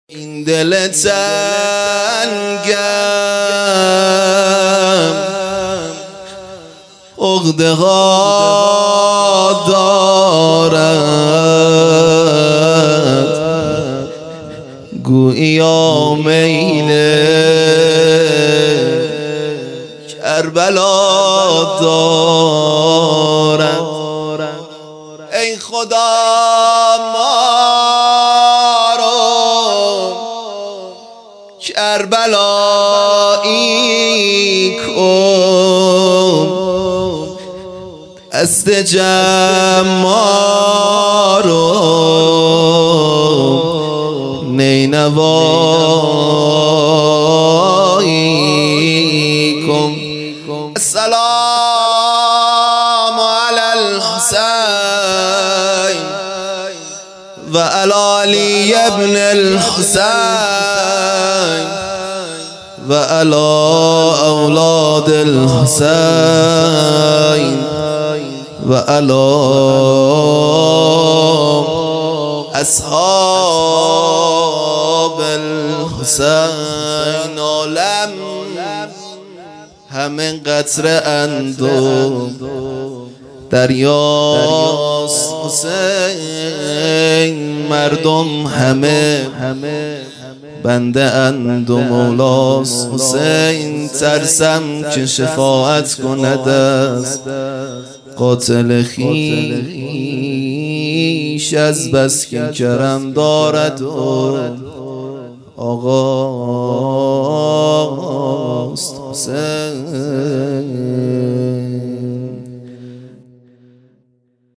خیمه گاه - هیئت بچه های فاطمه (س) - مدح | این دل تنگم عقده ها دارد
جشن نیمۀ شعبان